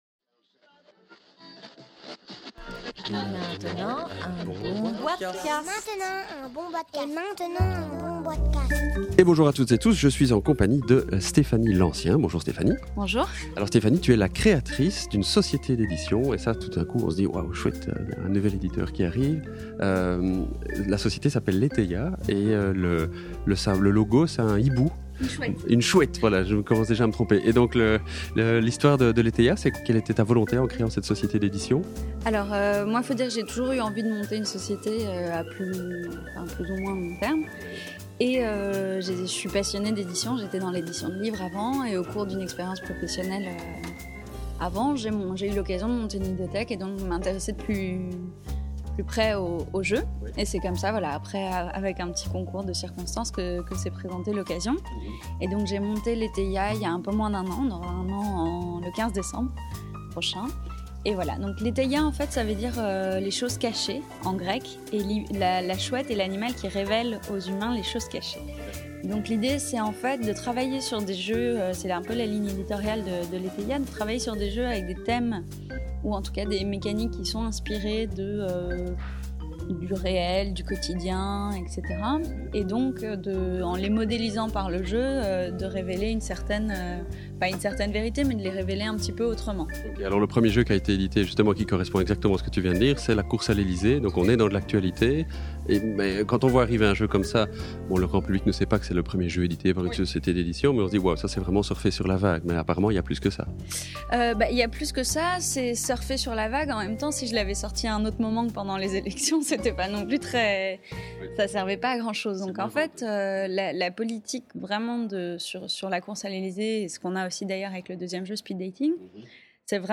(enregistré au Salon international du Jeu de Société de Essen – Octobre 2012)